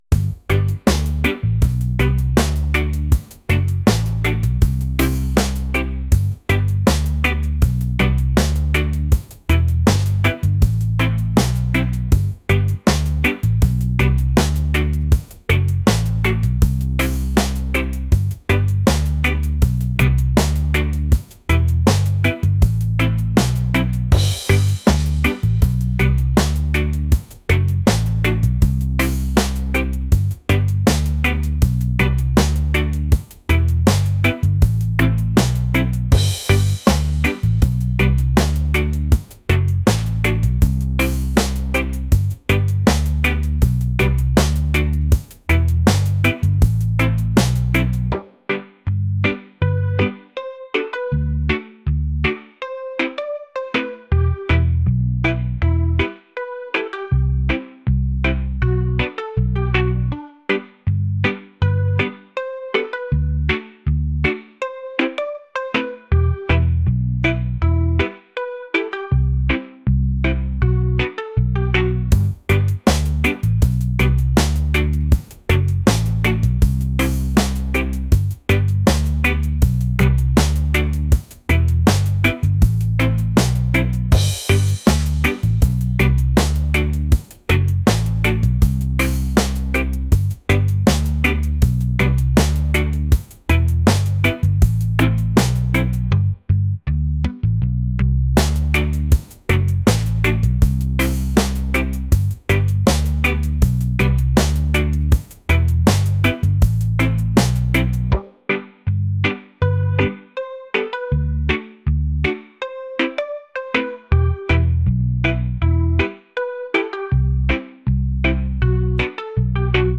reggae | lounge | soul & rnb